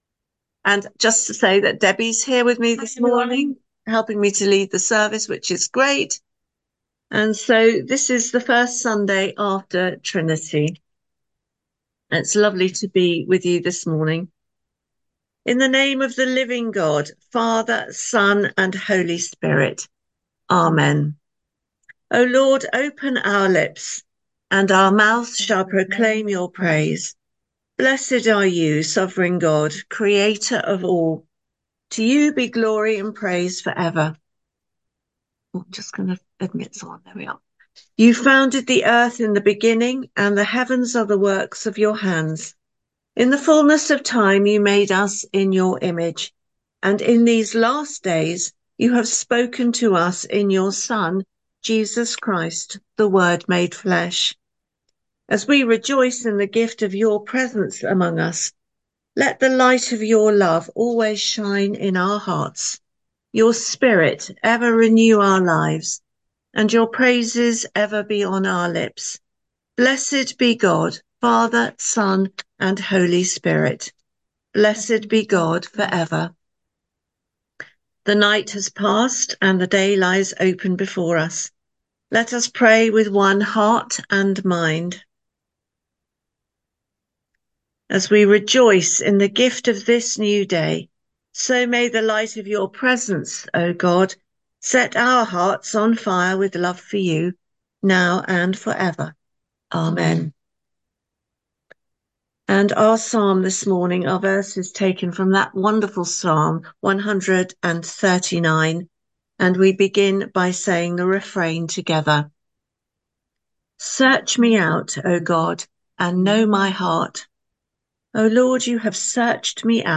Morning Prayer - Sun, 2 Jun 2024 (26:04 / 12.98MB)